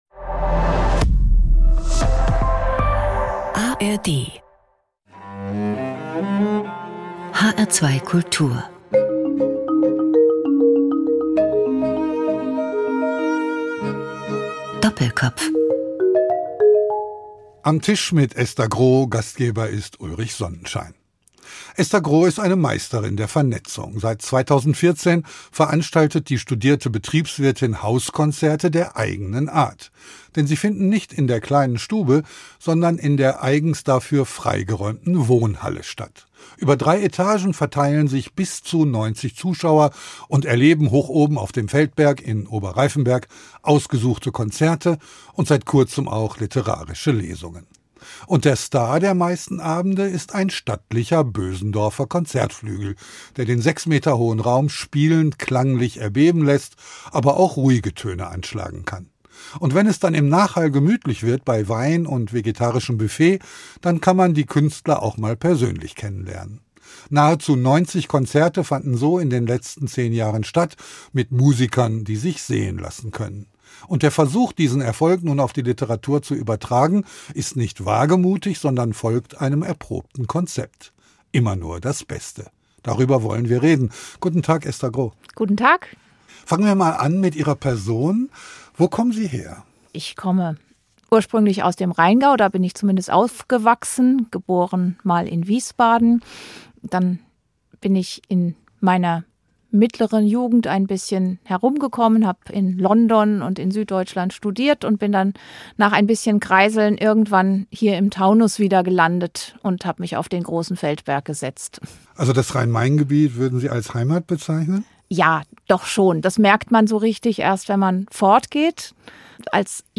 Interessante Zeitgenossen - Menschen, die etwas zu sagen haben, unterhalten sich 50 Minuten lang mit einem Gastgeber über ihre Arbeit und ihr Leben.